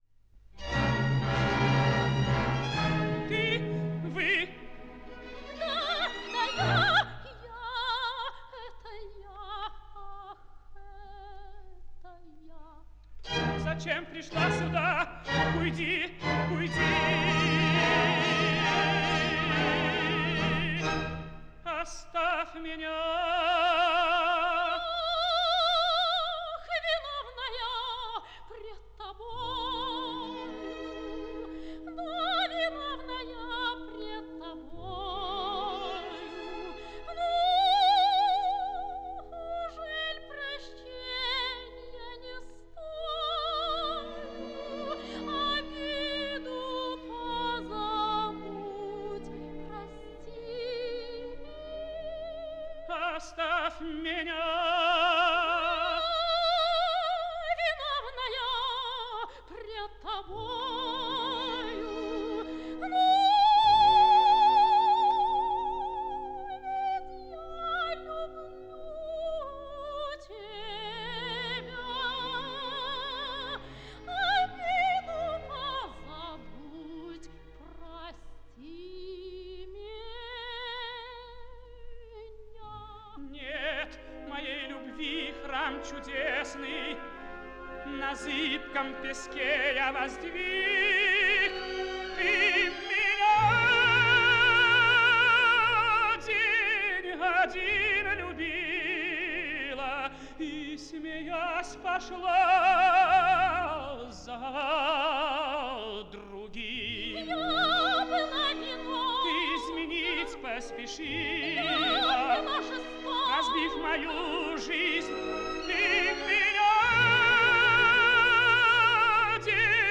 лирический тенор